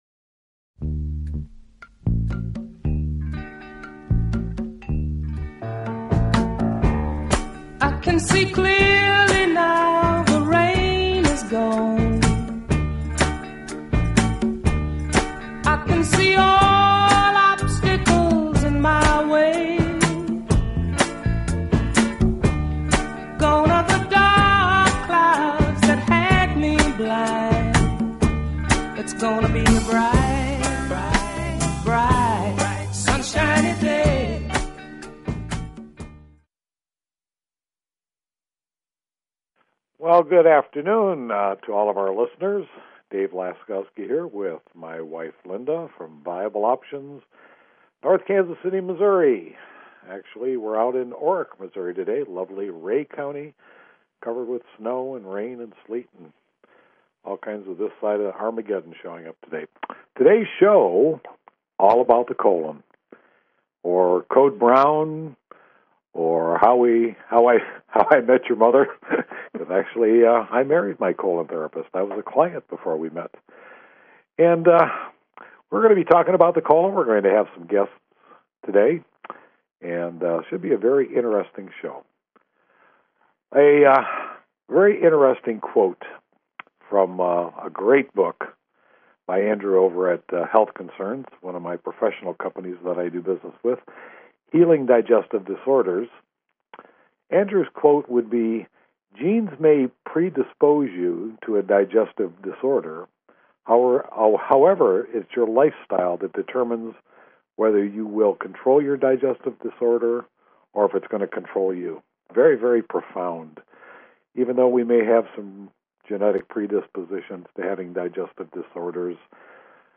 Talk Show Episode, Audio Podcast, Choices_Along_The_Way and Courtesy of BBS Radio on , show guests , about , categorized as